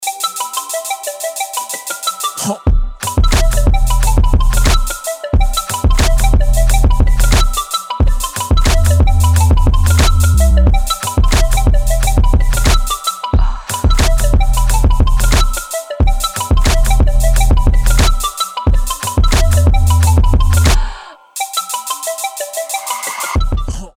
• Качество: 320, Stereo
мужской голос
ритмичные
без слов
Electronica
Downtempo
звонкие